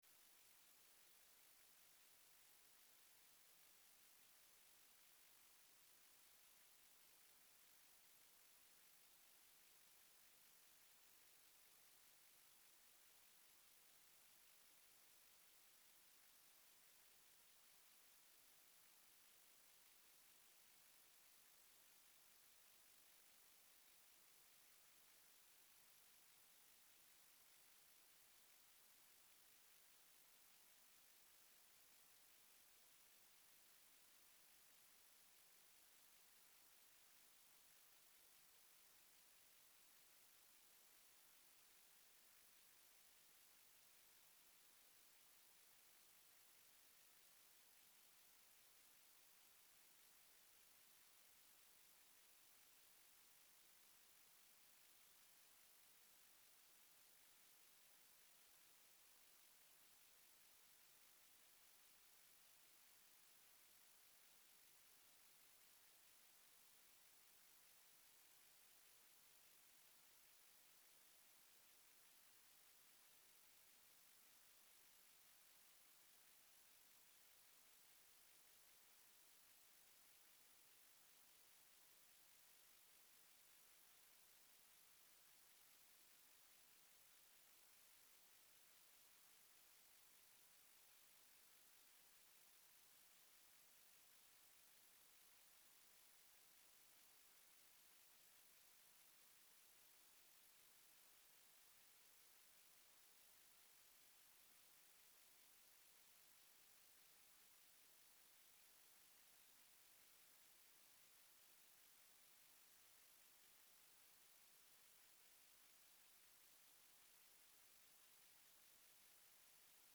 Commissievergadering 25 november 2020